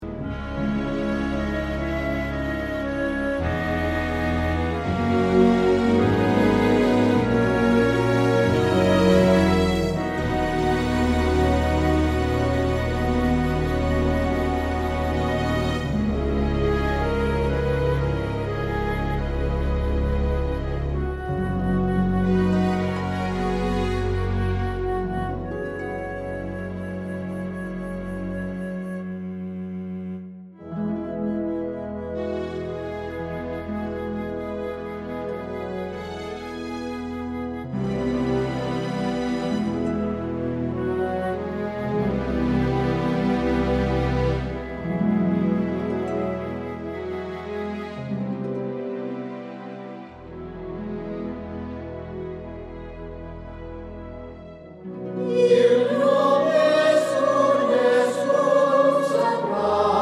4 Semitones Lower Easy Listening 3:07 Buy £1.50